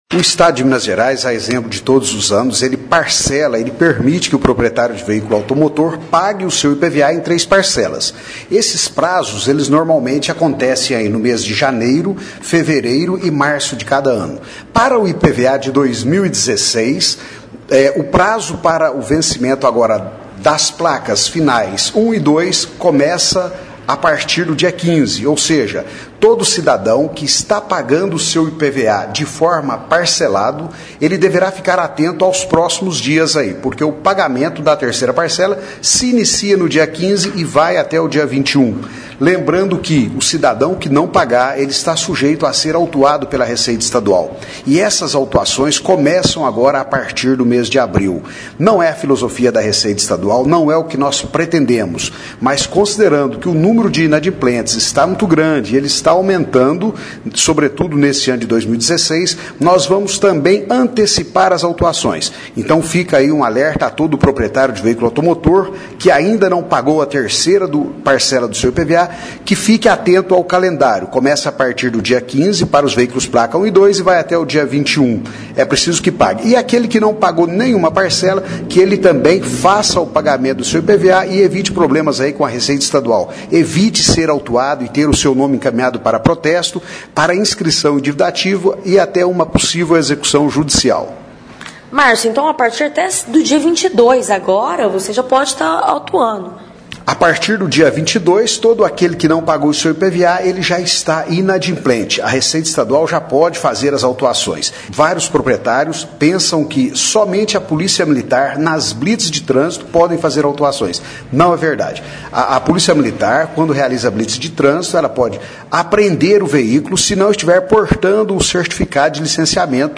Começa hoje (15/03), o prazo para o pagamento da 3ª parcela do IPVA-Imposto sobre a propriedade de veículos automotores. (Clique no player abaixo e ouça a entrevista). Além disso, o governo está promovendo mudanças no sistema de licenciamento dos veículos.